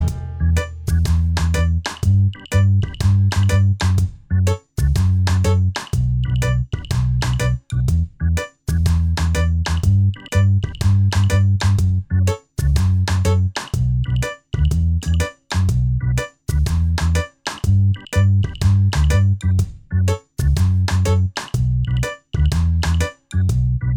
Minus Guitars And Organ Reggae 3:31 Buy £1.50